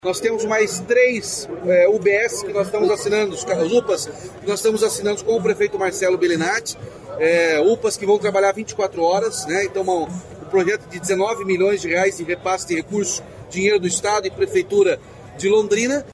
Sonora do governador Ratinho Junior sobre o anúncio de três novos Pronto Atendimento Municipais em Londrina